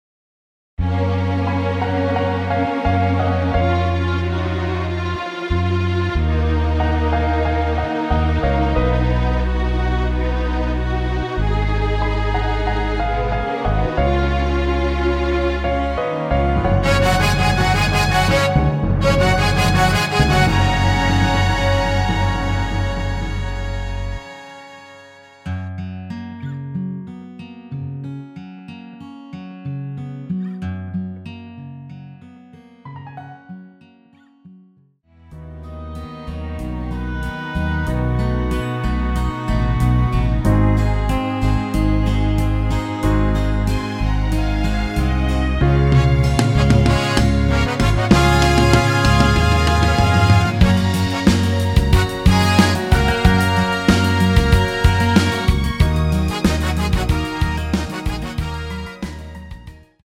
원키에서 (-1)내린 MR 입니다.(미리듣기 참조)
F#
앞부분30초, 뒷부분30초씩 편집해서 올려 드리고 있습니다.
중간에 음이 끈어지고 다시 나오는 이유는